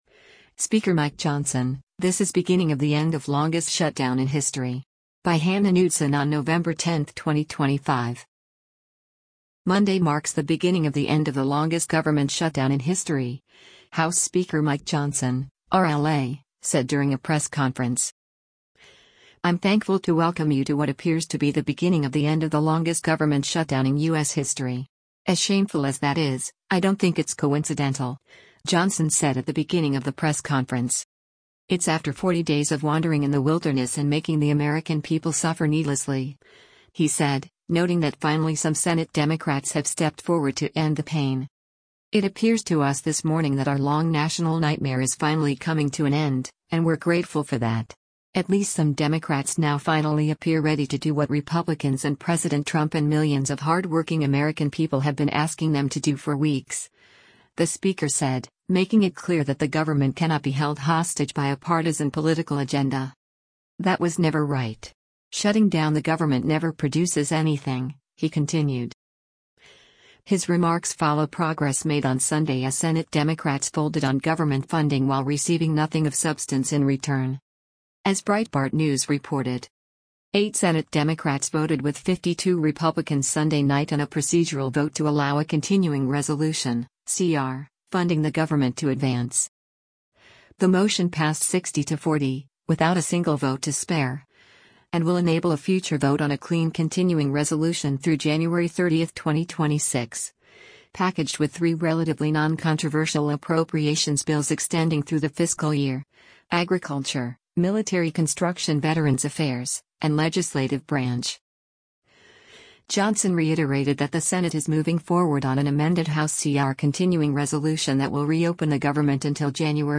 Monday marks the “beginning of the end” of the longest government shutdown in history, House Speaker Mike Johnson (R-LA) said during a press conference.